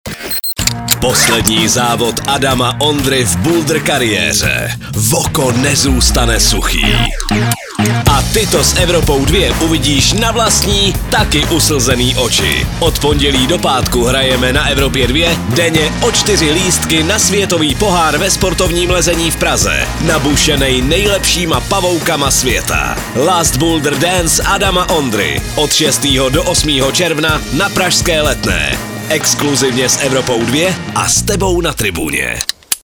promo_ifsc_climbing_wc_prague_2025.mp3